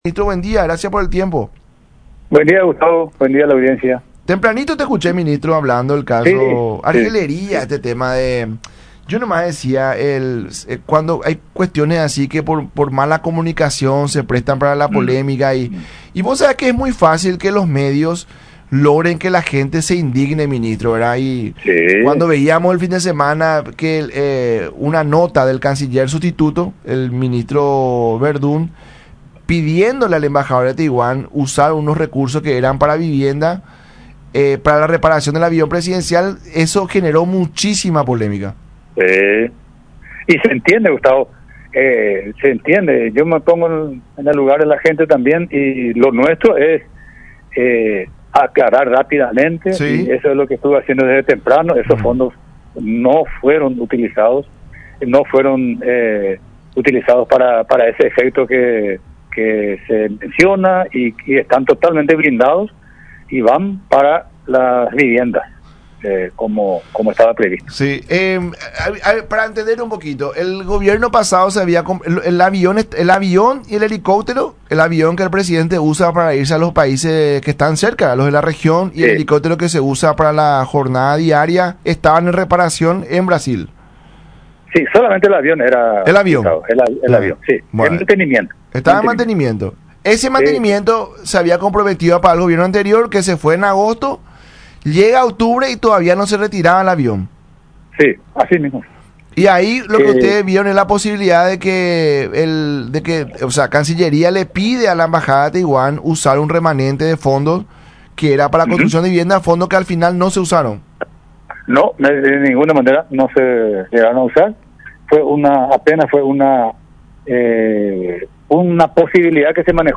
“El avión estaba en Brasil y había un atraso de mucho tiempo y vio como una posibilidad pero finalmente decidió no usar ese dinero”, mencionó el ministro de Defensa Nacional, Óscar González en el programa “La Mañana De Unión” por radio La Unión y Unión TV.